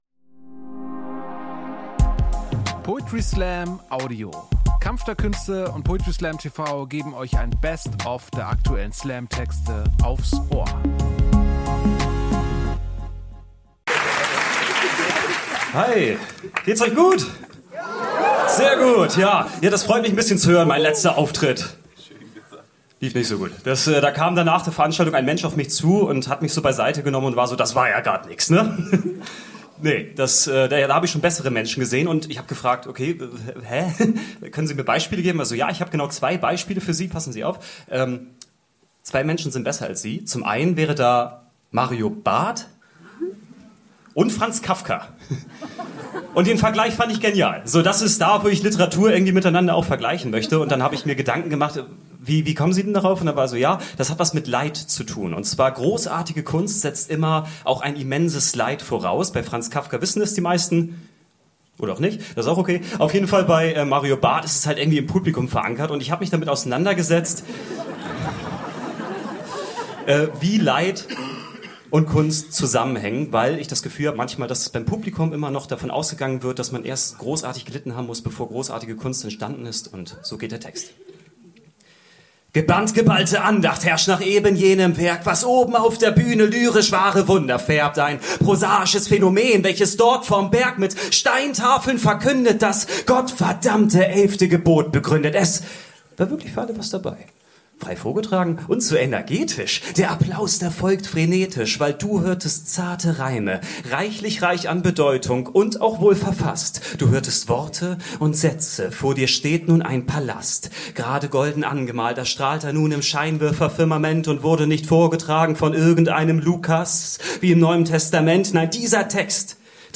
Theater, Hamburg Poetry Slam TV Website: